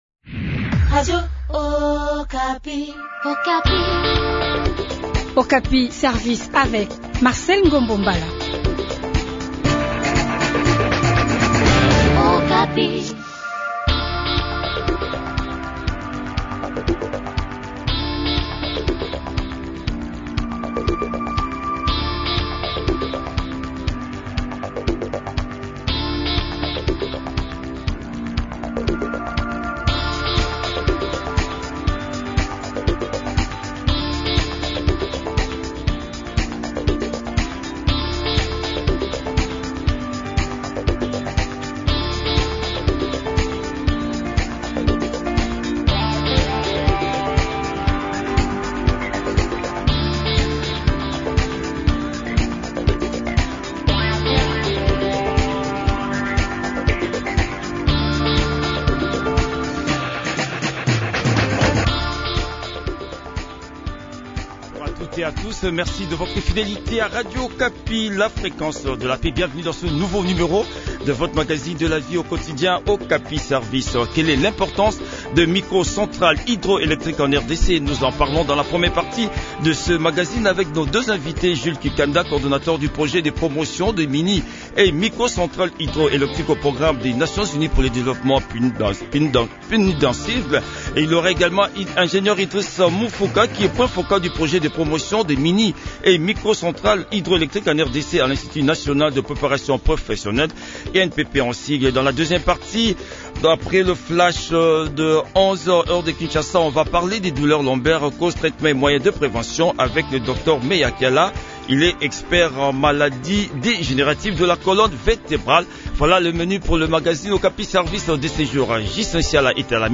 a aussi participé à cette interview.